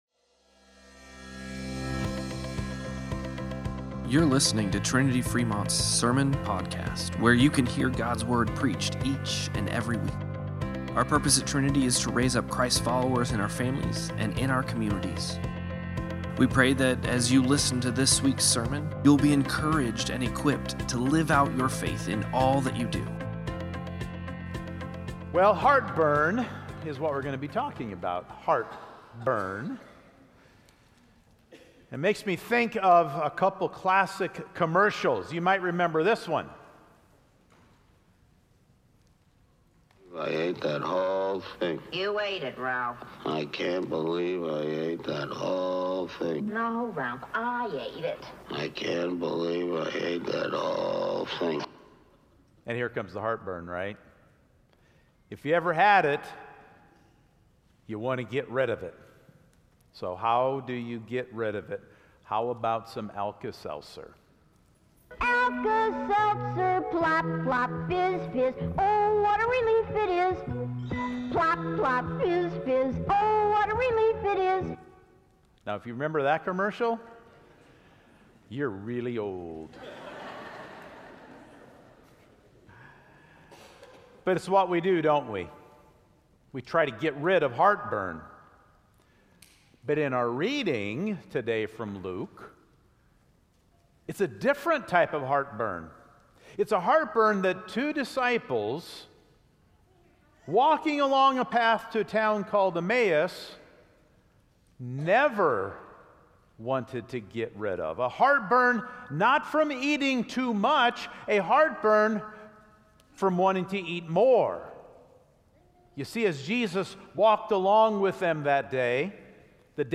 Sermon-Podcast-10-26.mp3